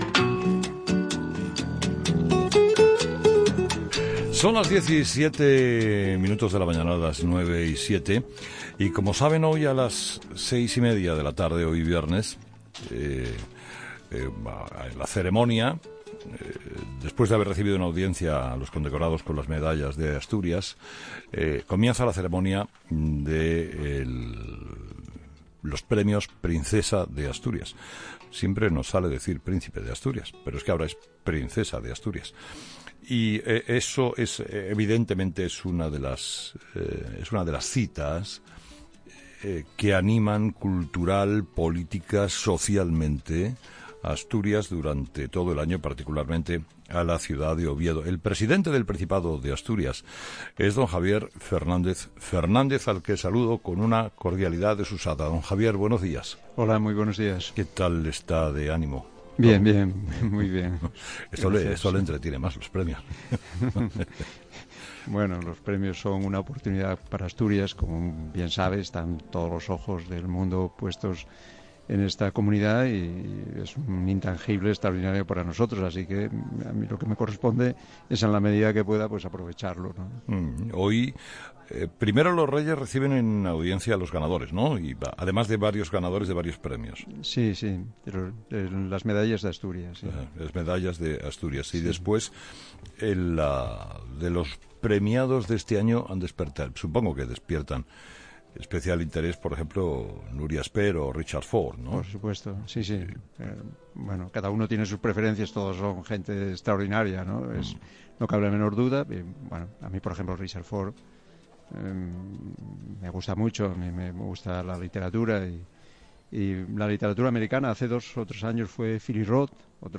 Entrevista a Javier Fernández